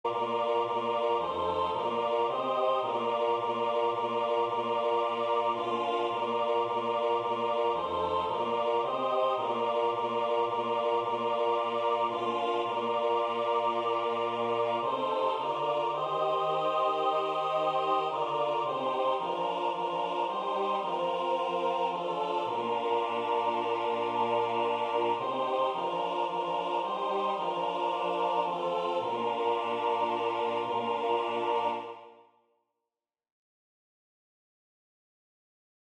They do not feature live vocals, but are merely presented for readers to hear a sonic reference to the tonic sol-fa scores featured in the book.